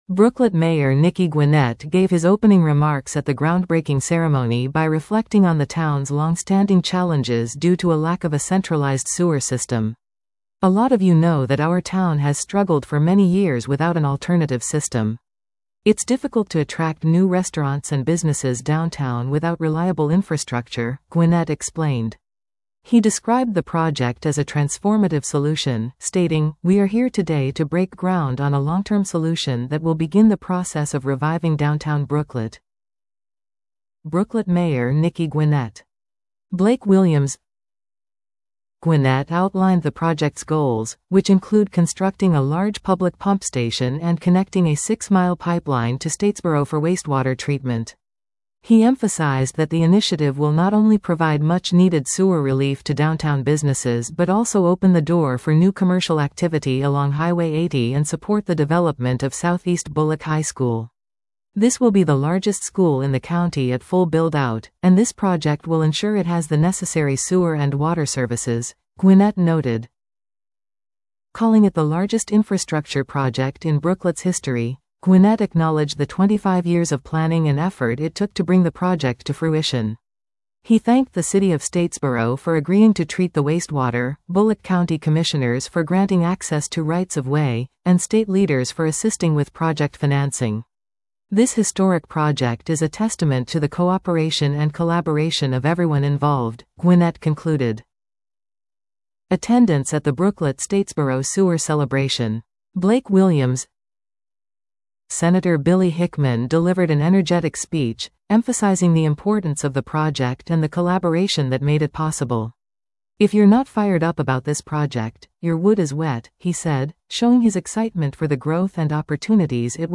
Brooklet Mayor Nicky Gwinnett gave his opening remarks at the groundbreaking ceremony by reflecting on the town’s longstanding challenges due to a lack of a centralized sewer system.